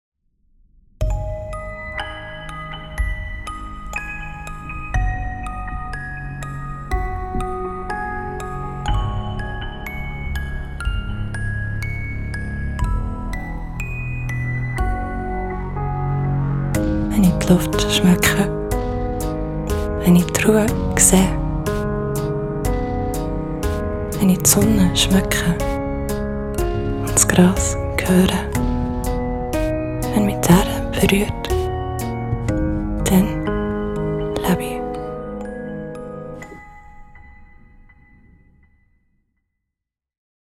Sprecherin Schweizerdeutsch, Sprecherin Berndeutsch, Schweizer Sprecherin in Hamburg
Sprechprobe: Werbung (Muttersprache):
Calida_V1_Schwizerduetsch.mp3